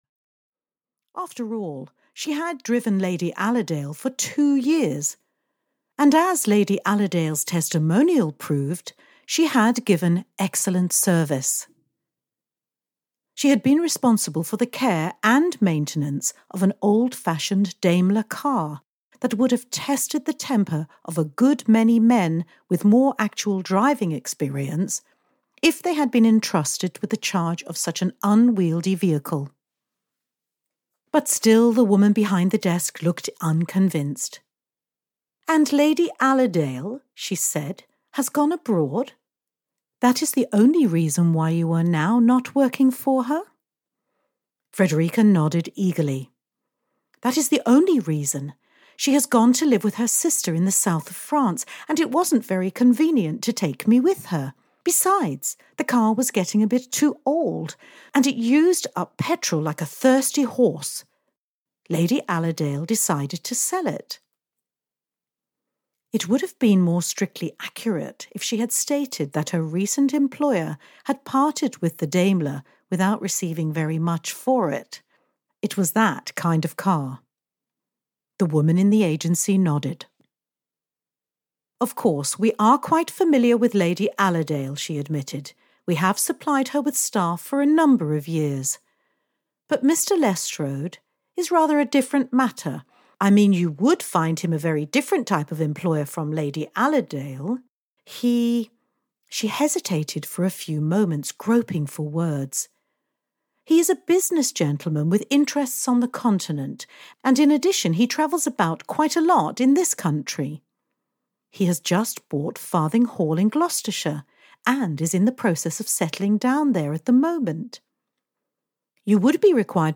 The Marriage Wheel (EN) audiokniha
Ukázka z knihy